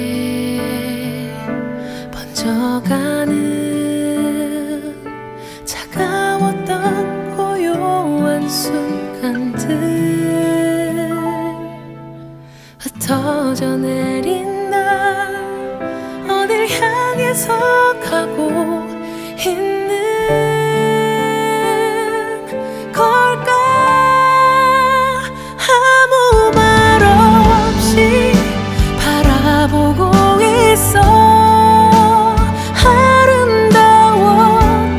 Жанр: Музыка из фильмов / Саундтреки
# TV Soundtrack